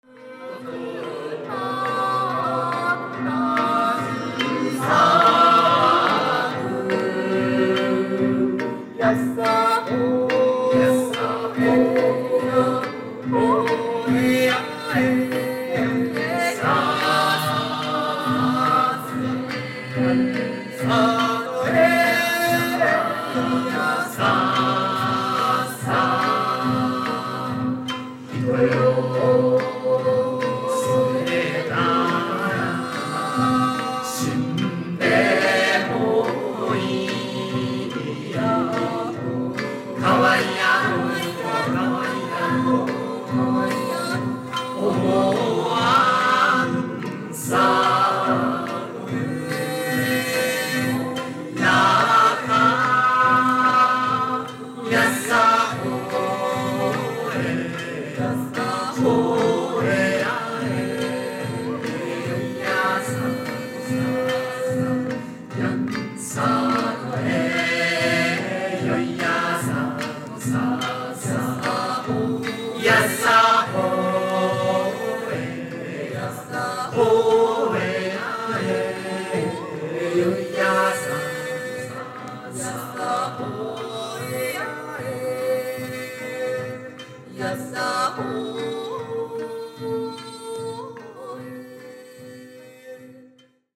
(鳥取県民謡)
Japanese Traditional